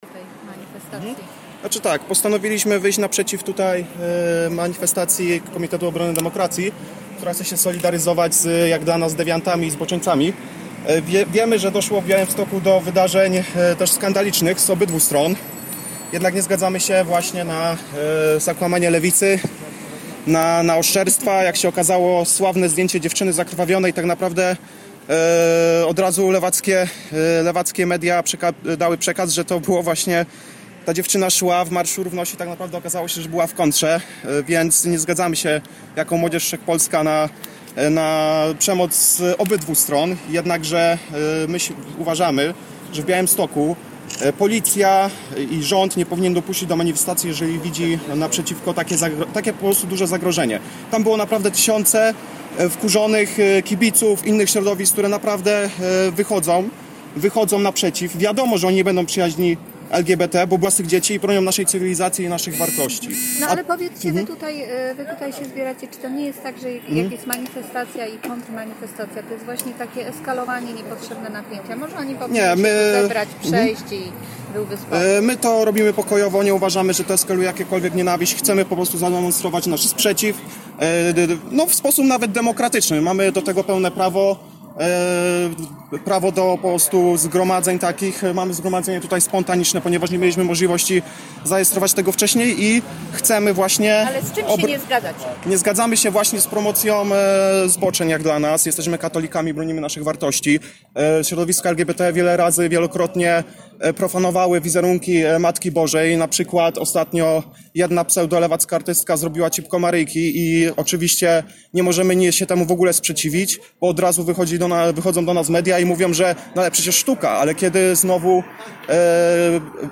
Po drugiej stronie ulicy Młodzież Wszechpolska zorganizowała kontrdemonstrację.
W intencji odmówili dziesiątkę różańca i skandowali ” Wielka Polska katolicka, nie tęczowa,nie laicka „, „Chłopak dziewczyna normalna rodzina” oraz „Bóg, Honor, Ojczyzna”.